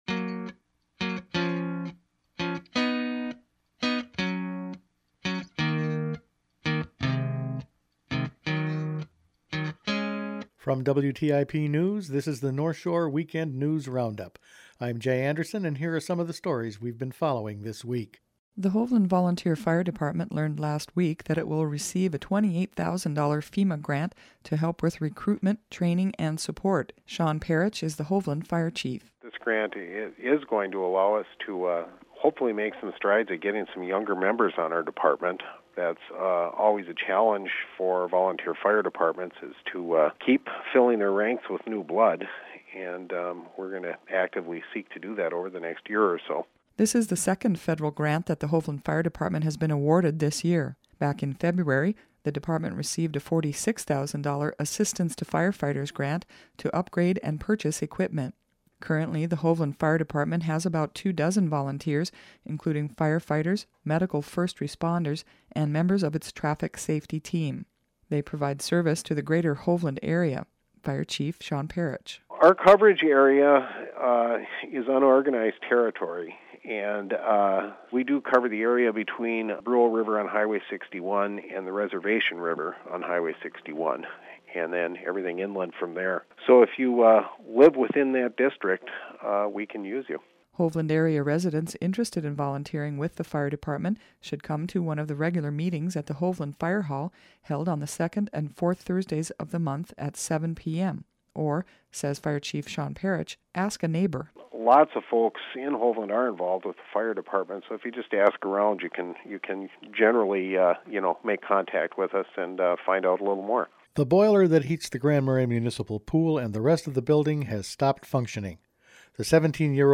Each weekend the WTIP News Department assembles the week’s important news stories to play here on North Shore Weekend. Among other things, this week we’ve been covering fire departments, pool boilers and the ISD166 levy referendum.